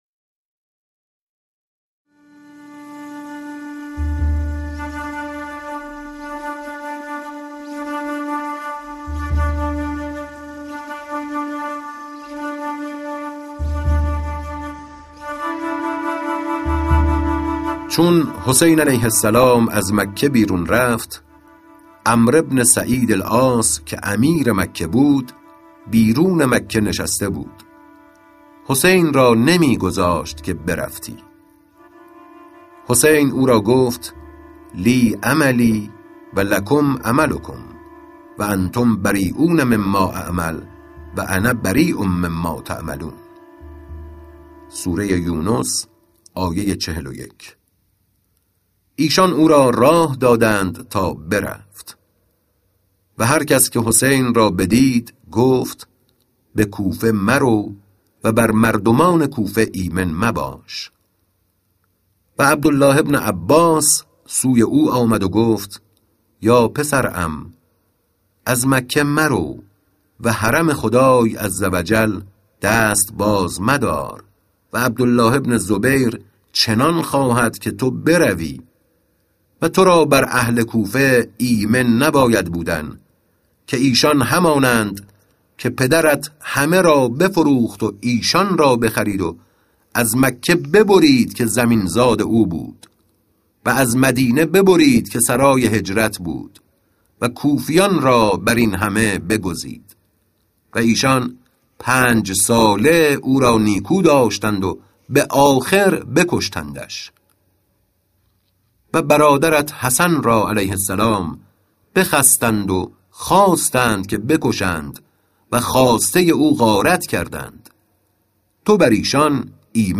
راوی: ساعد باقری